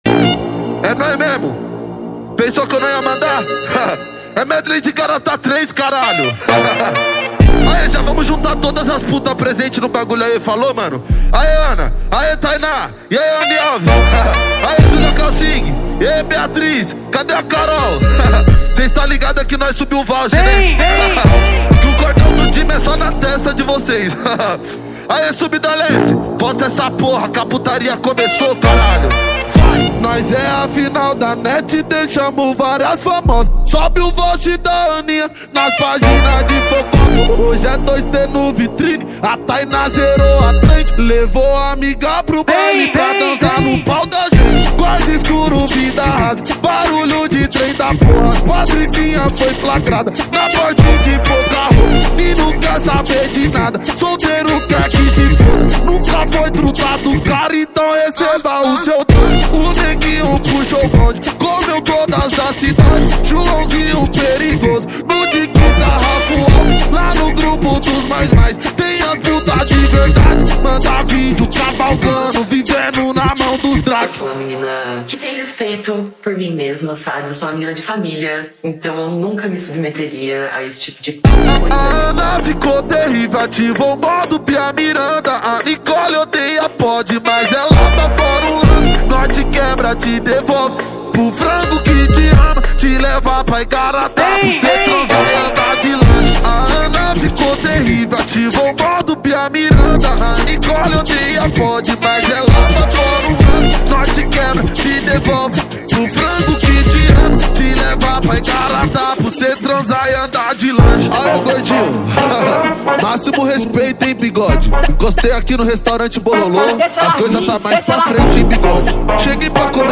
2025-01-15 17:13:13 Gênero: MPB Views